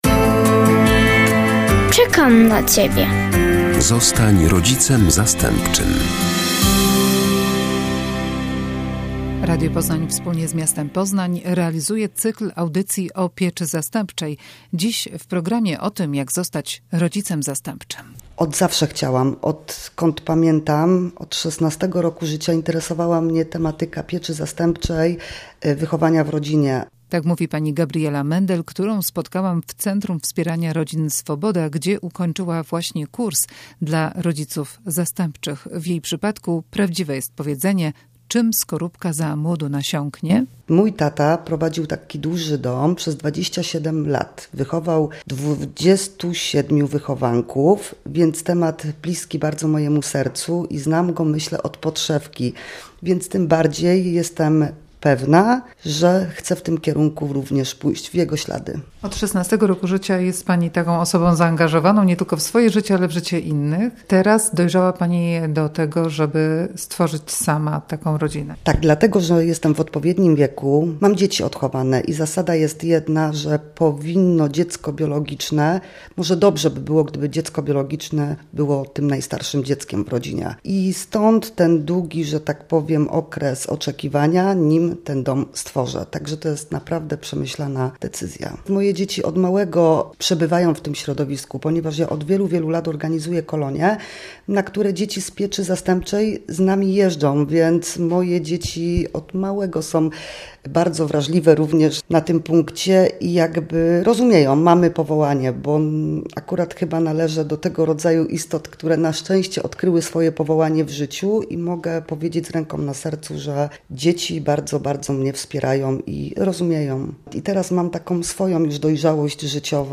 Trzeci odcinek cyklu audycji o rodzinach zastępczych.
Jakie trzeba spełnić wymogi formalne, jakie dokumenty należy przygotować oraz jak wygląda szkolenie. Opowiadają rodzice zastępczy - zarówno ci, którzy dopiero zakończyli kurs, jak i ci, którzy już mają pod swoją opieką dziecko.